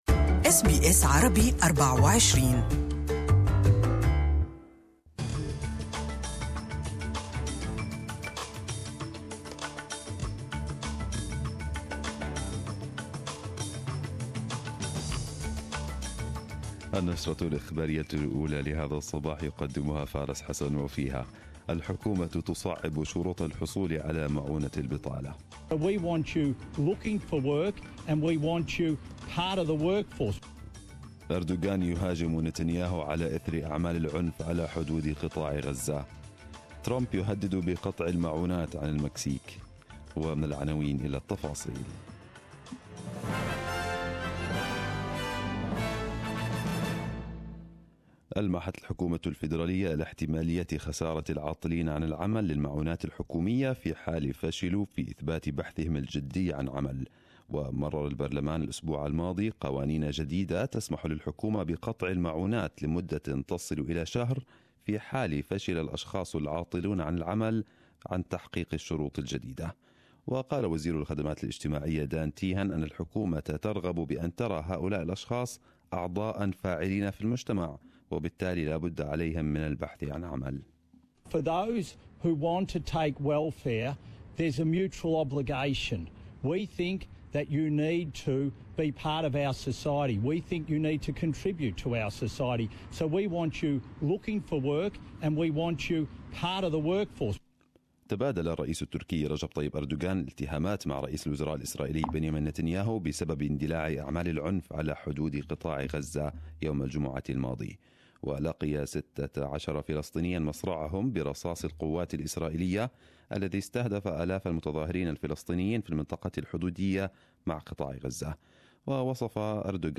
Arabic News Bulletin 02/04/2018